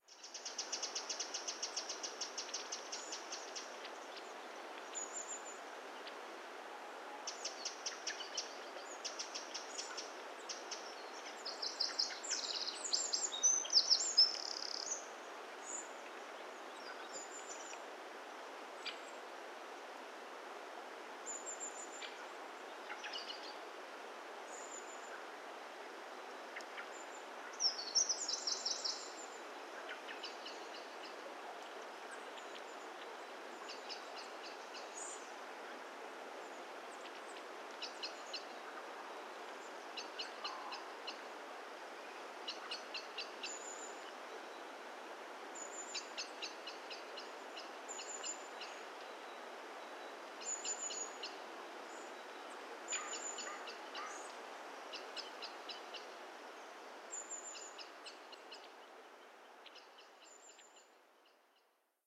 Ambiente de bosque en invierno
ambiente
bosque
Sonidos: Animales
Sonidos: Rural